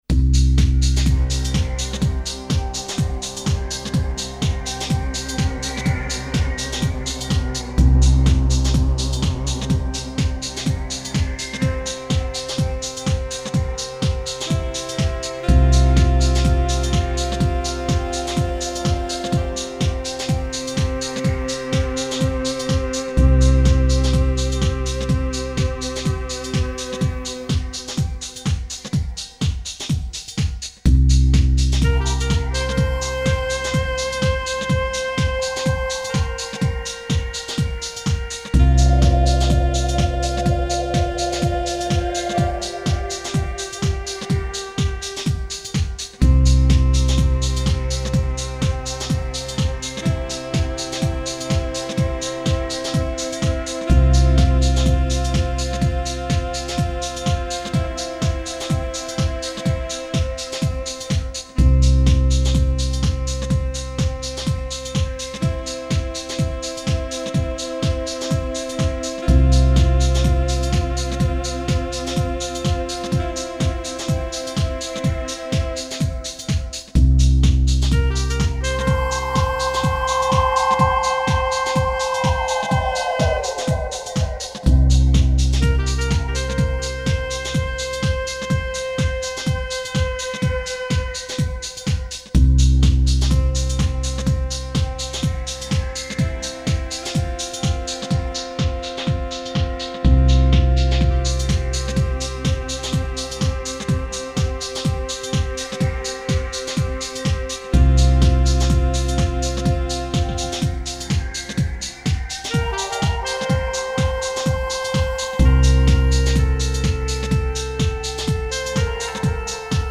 Tempo: 125 bpm / Datum: 07.06.2018